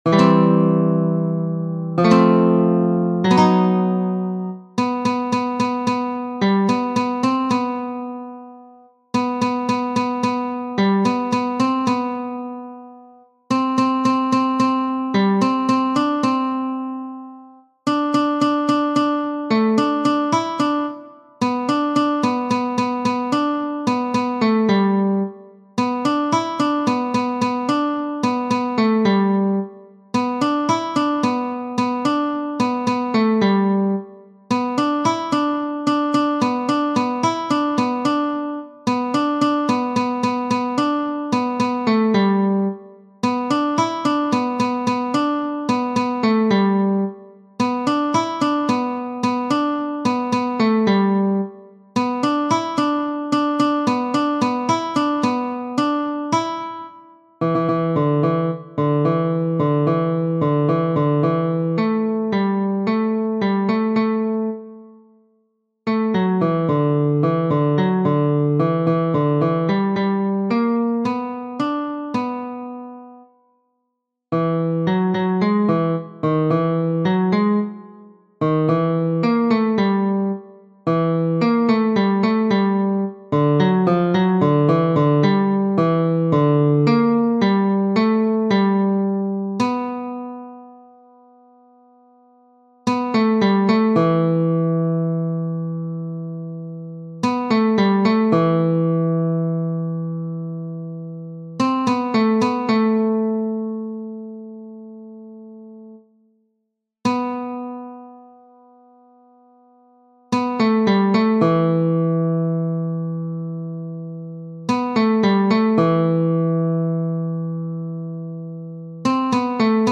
Glockenspiel.